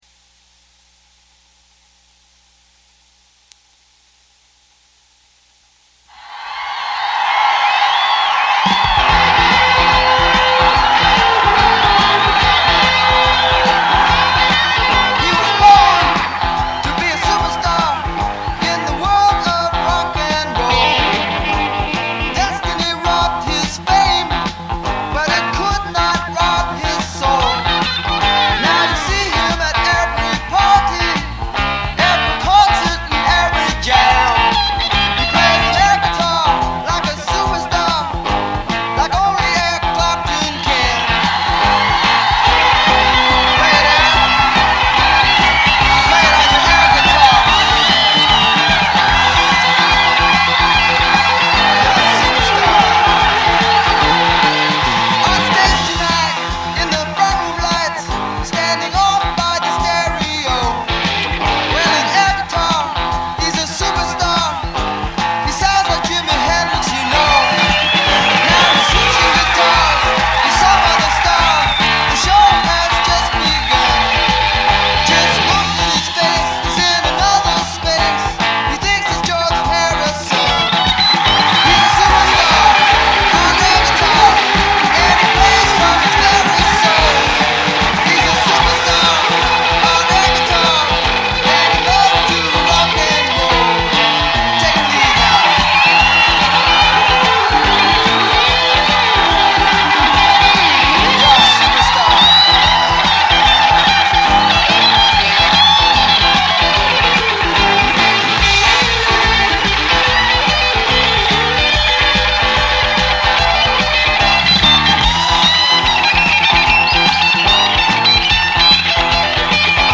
It's a really rocking tune!